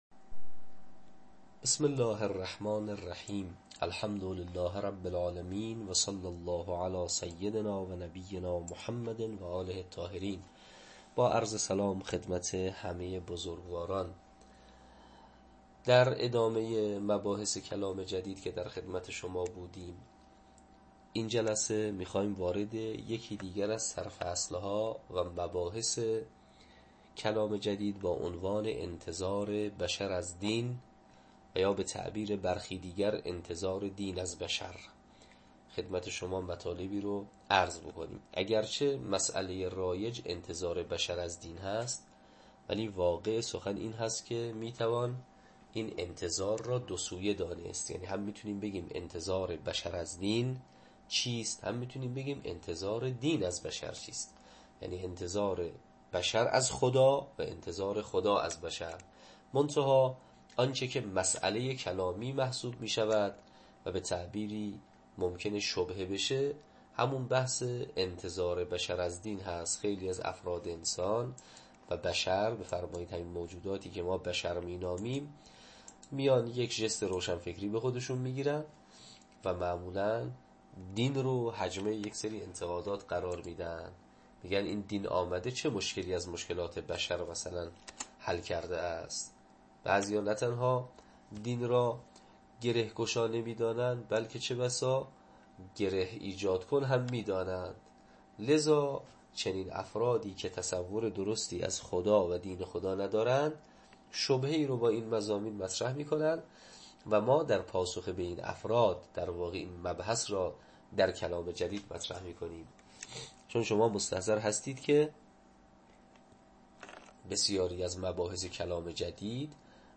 تدریس کلام جدید